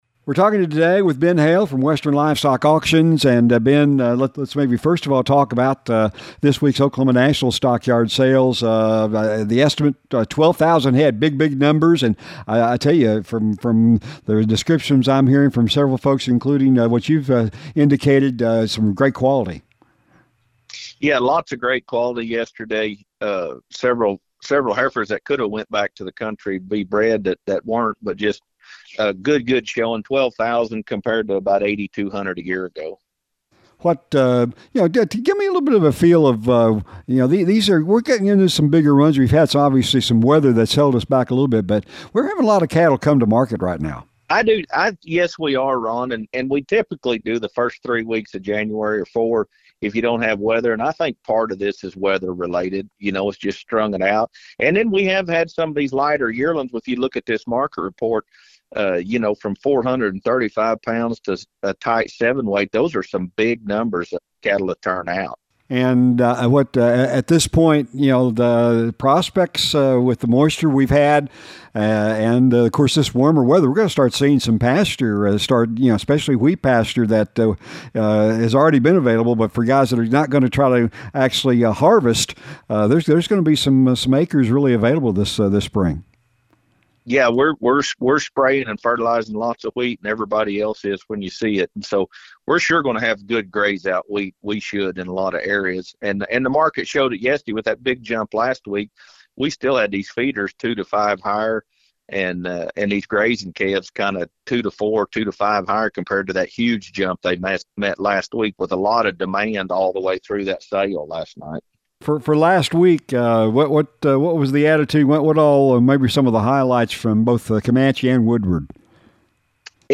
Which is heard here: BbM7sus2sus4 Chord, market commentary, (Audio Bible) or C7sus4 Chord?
market commentary